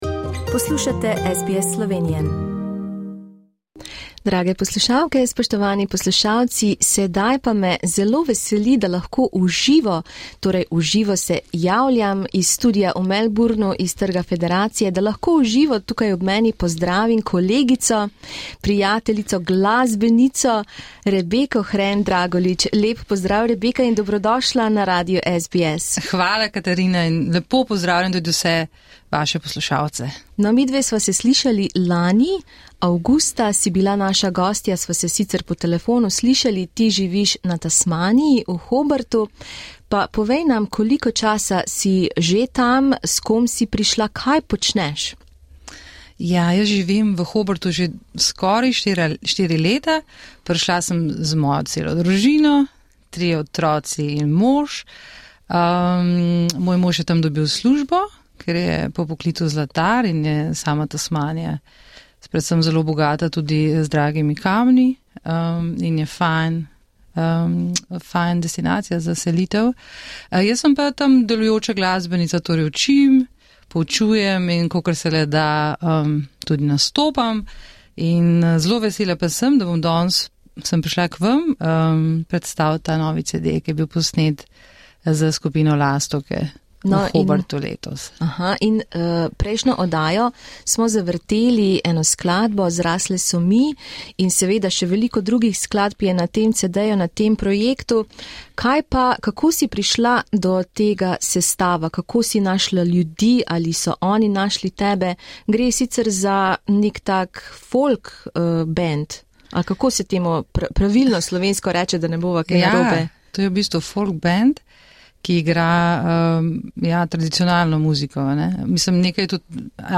V soboto, 1.oktobra 2022, smo v studiu Radia SBS v Melbournu v živo gostili slovensko glasbenico